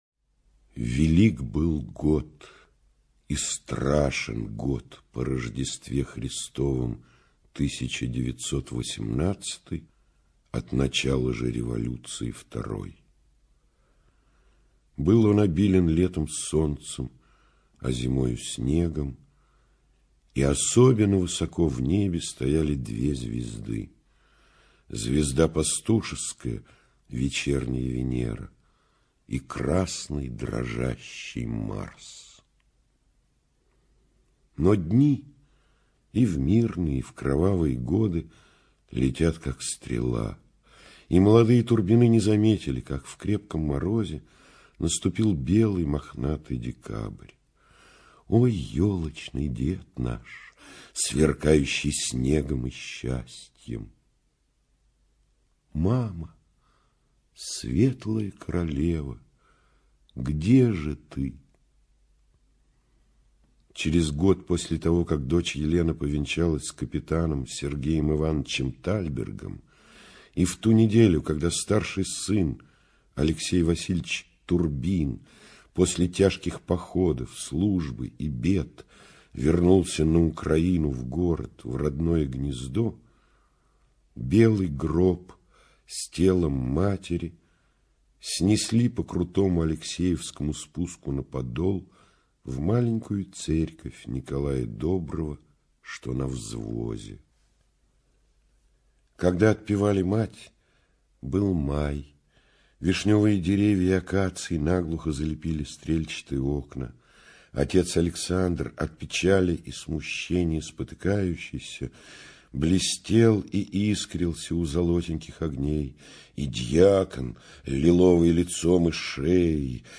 ЧитаетКваша И.
Булгаков М - Белая гвардия. Отрывки (Кваша И.)(preview).mp3